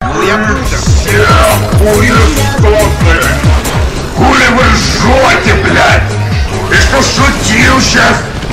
• Качество: 128, Stereo
мужской голос
громкие
злые
агрессивные
bassboosted